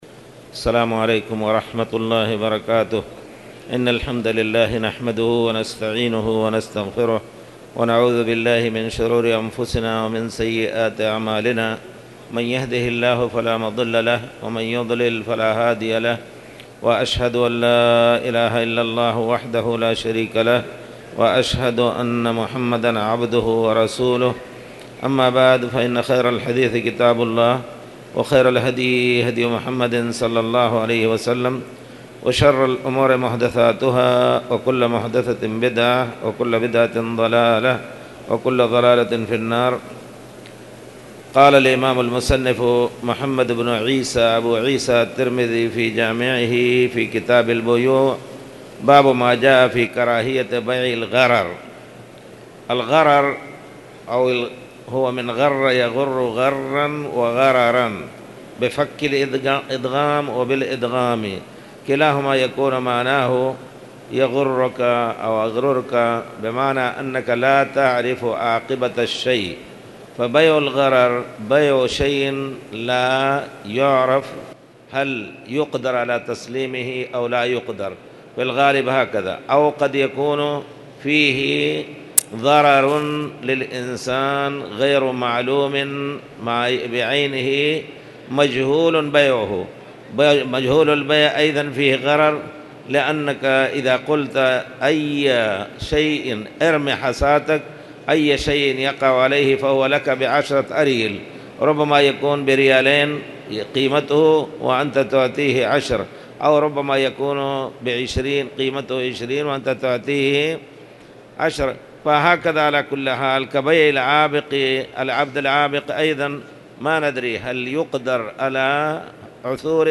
تاريخ النشر ٨ ربيع الأول ١٤٣٨ هـ المكان: المسجد الحرام الشيخ